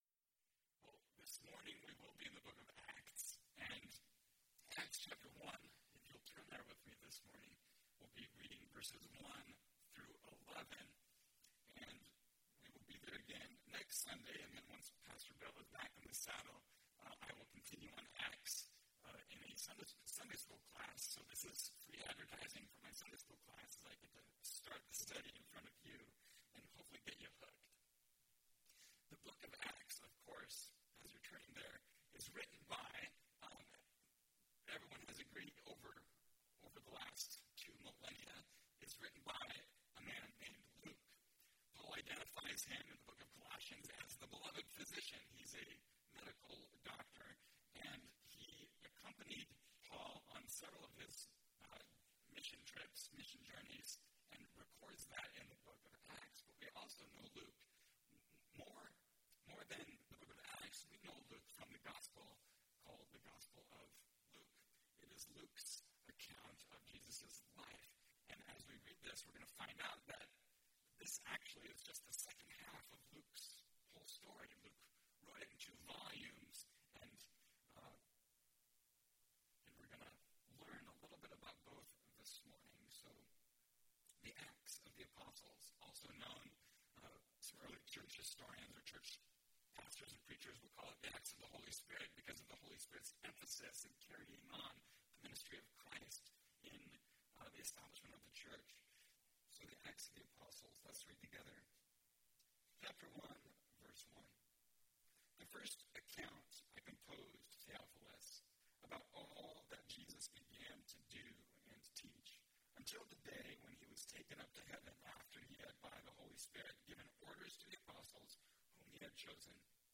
Not Over Yet (Acts 1:1-11) – Mountain View Baptist Church